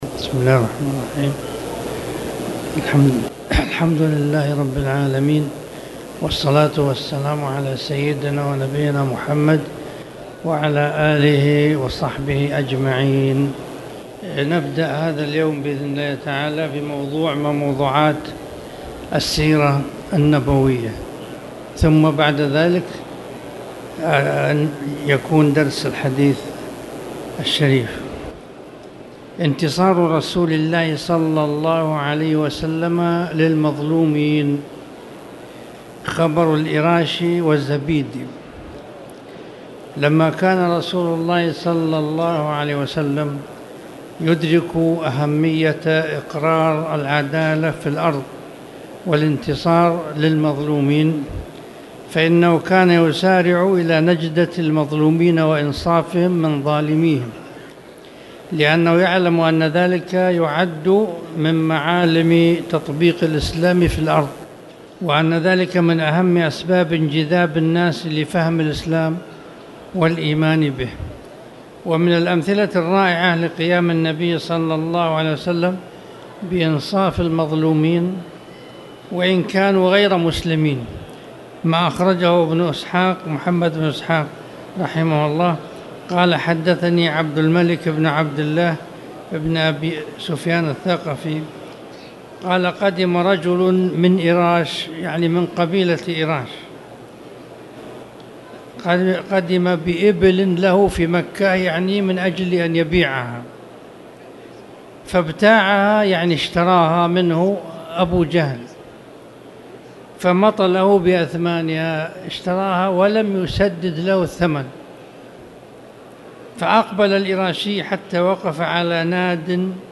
تاريخ النشر ١١ شعبان ١٤٣٨ هـ المكان: المسجد الحرام الشيخ